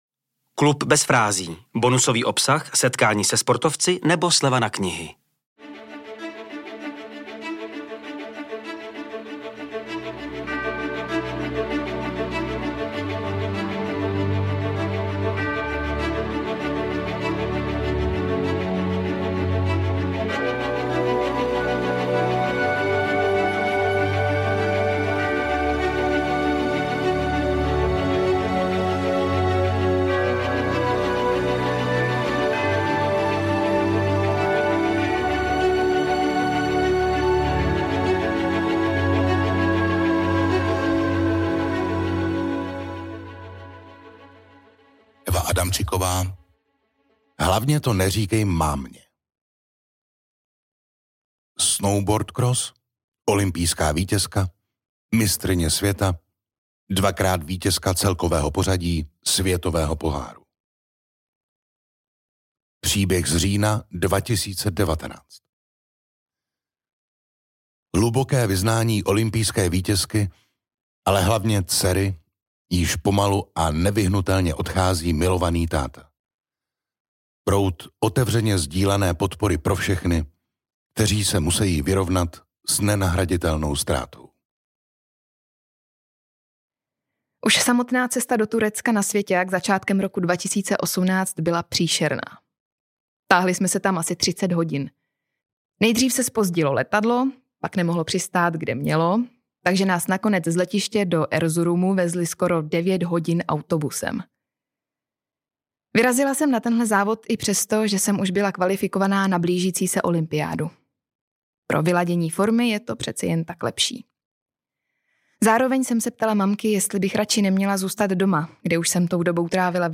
O tom je příběh Evy Adamczkykové , který pro vás krásně namluvila Veronika Khek Kubařová .